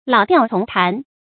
注音：ㄌㄠˇ ㄉㄧㄠˋ ㄔㄨㄙˊ ㄊㄢˊ
老調重彈的讀法